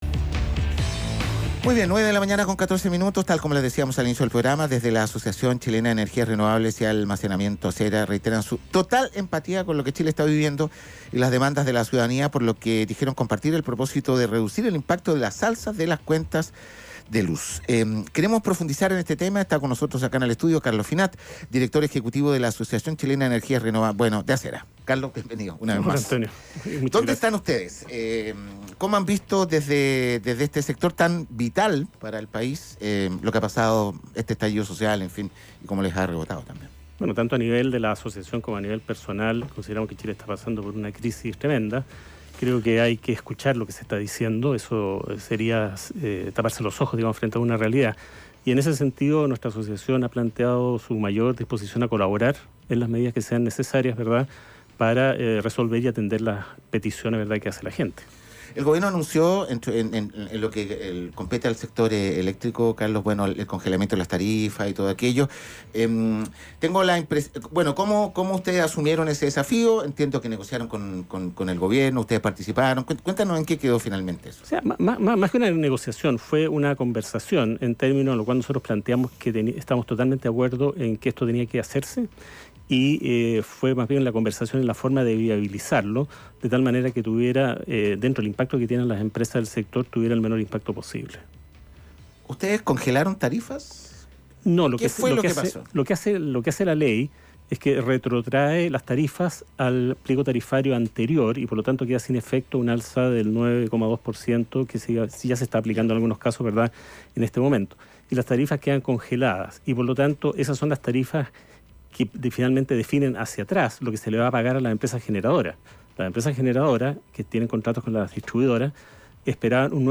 en entrevista con Radio Futuro.